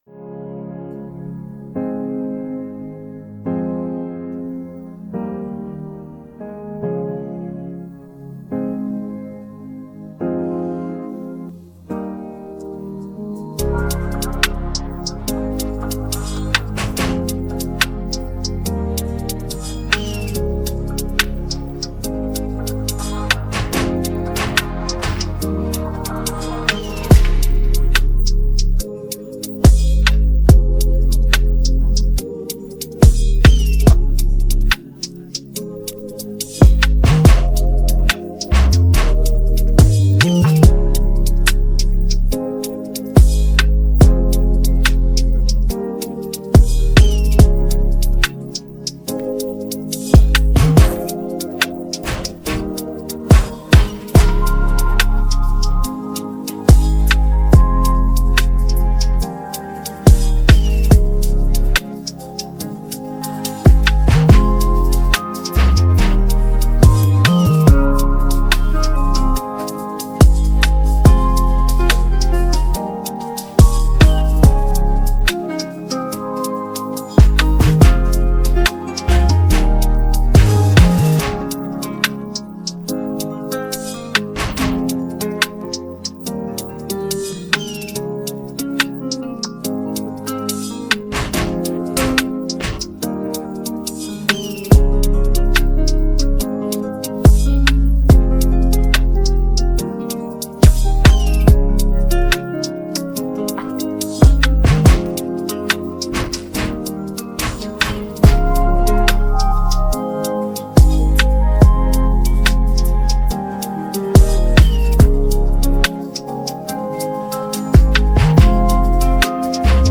Hip hophiphop trap beats